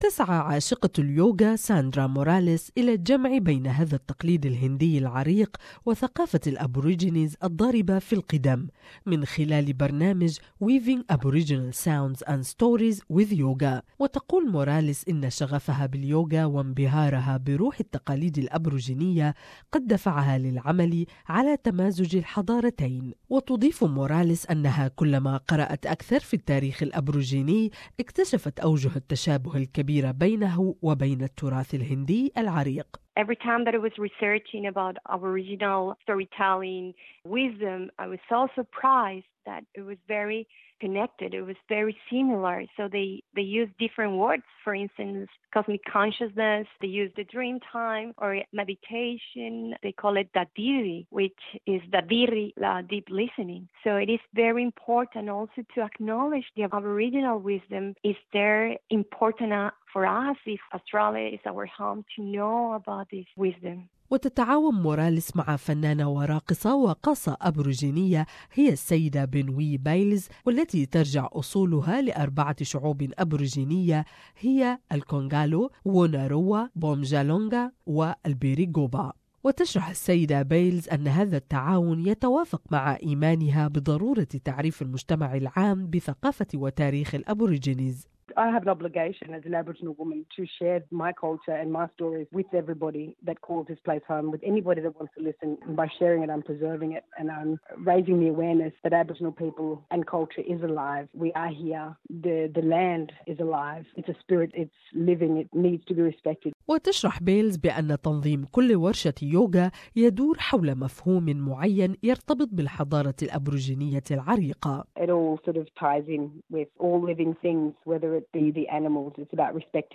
A Sydney-based yoga organisation is fusing Indian and Indigenous yoga traditions, incorporating the spirit of Aboriginal Australia into the ancient practice. More in this report